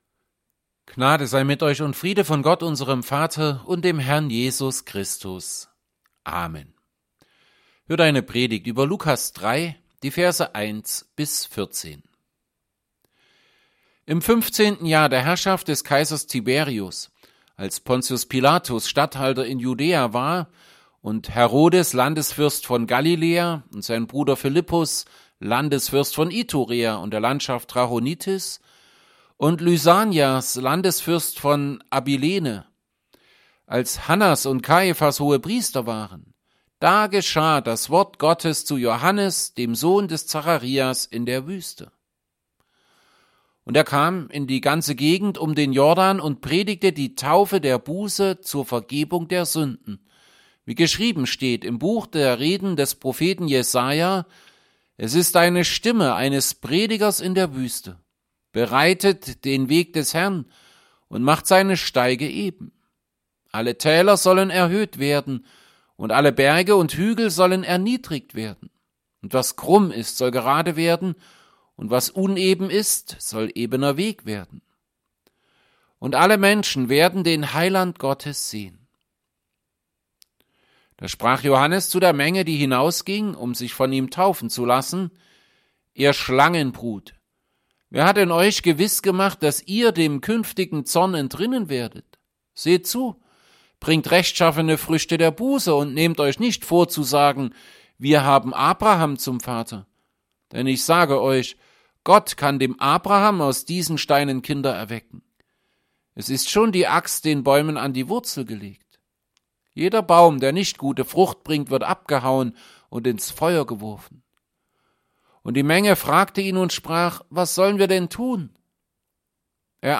Predigt_zu_Lukas_3_1b14.mp3